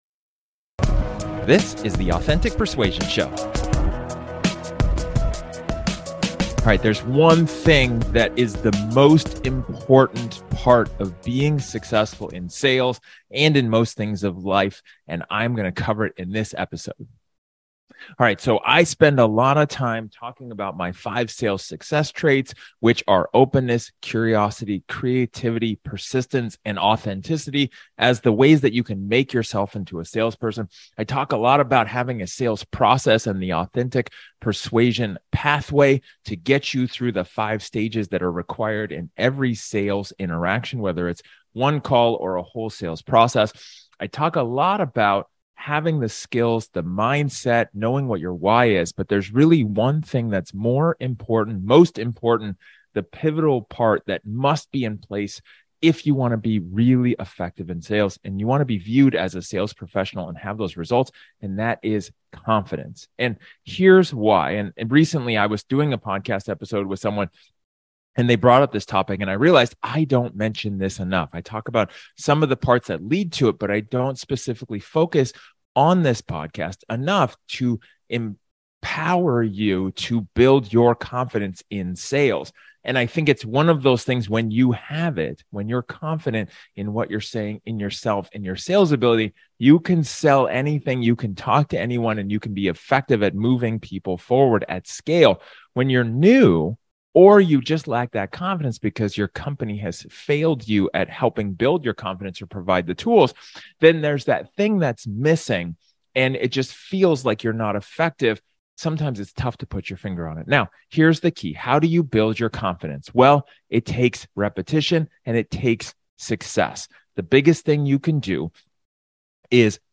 In this solo episode, I talk about one key to success in sales, which is confidence.